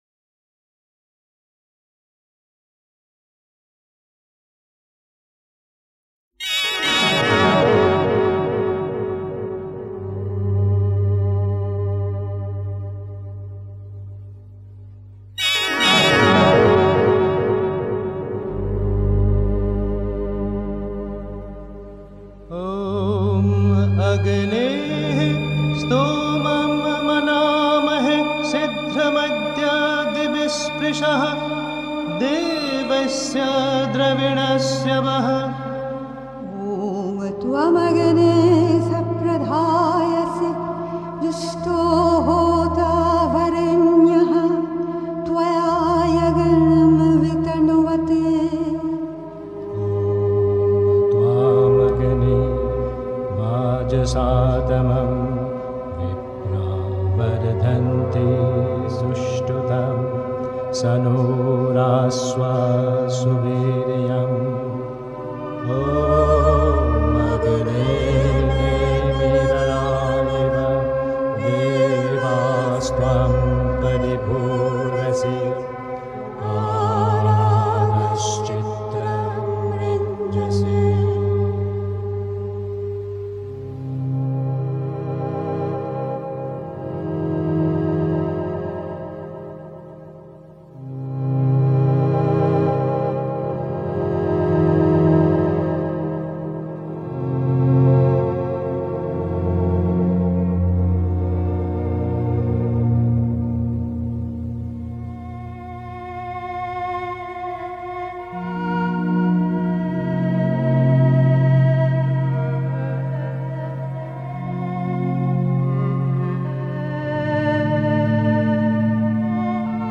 Listen Top Shows Blog Discover MEDITATION 204.
Die seelische und spirituelle Umwandlung (Sri Aurobindo, CWSA, Vol. 29, p. 307) 3. Zwölf Minuten Stille.